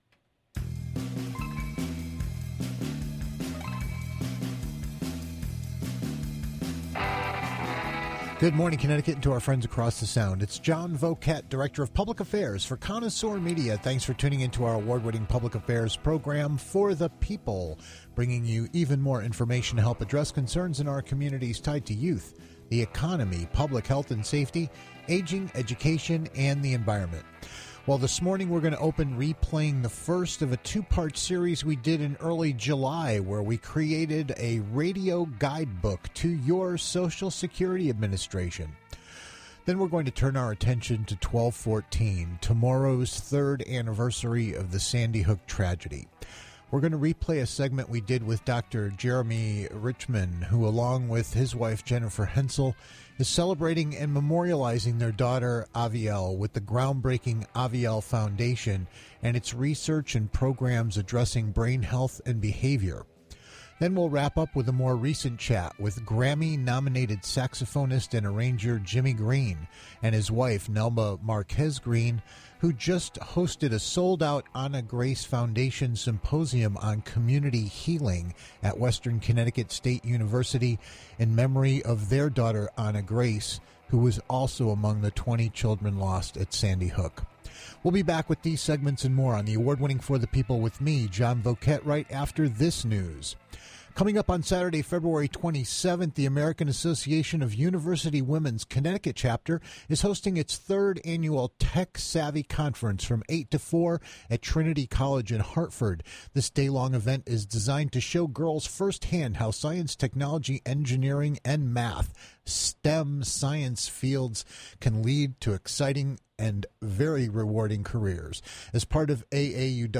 replays two interviews